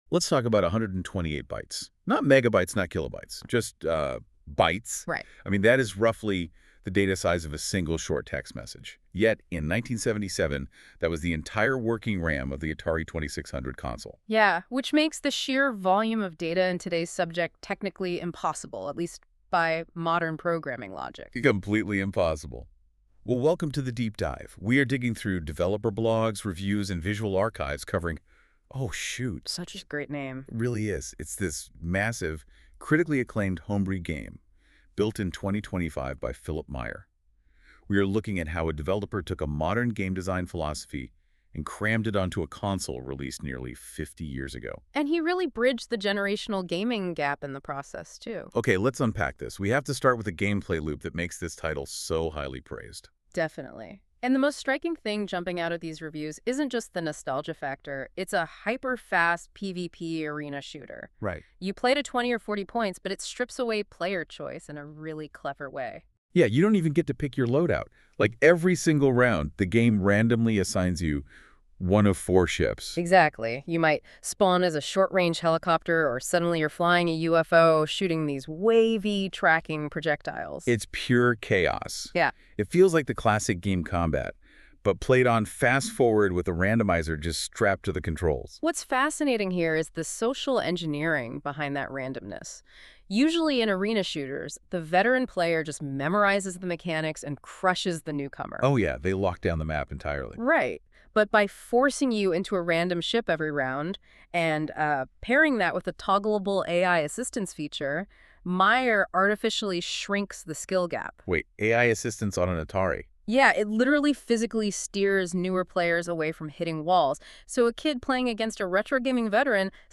On this page at the bottom is a “play” button where you can listen to the AI generated “short” podcast version of a discussion generated using Googles Notebook LM AI software.